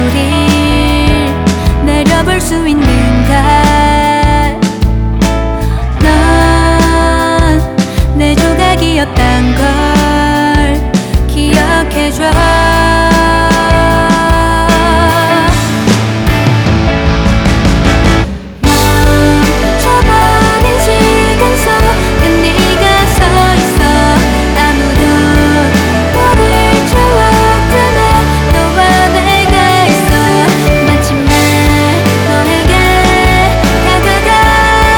Жанр: Поп музыка / Рок